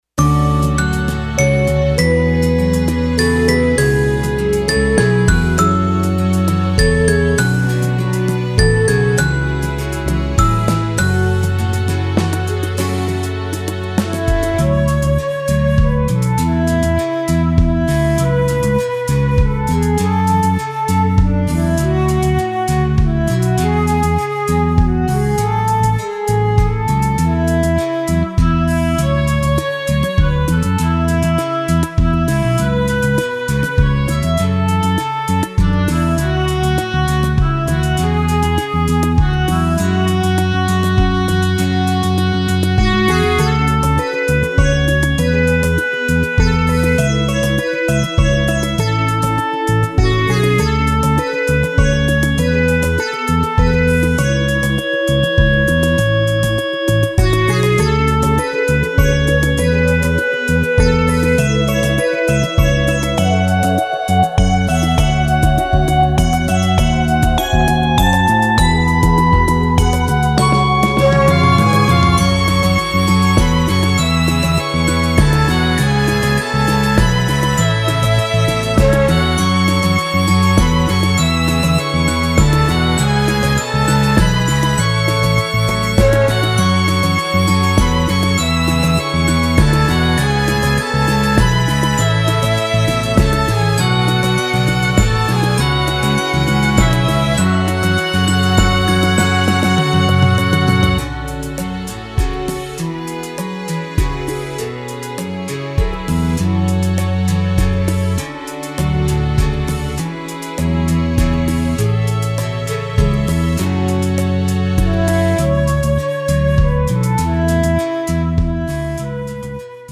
フリーBGM フィールド・ダンジョン フィールド
フェードアウト版のmp3を、こちらのページにて無料で配布しています。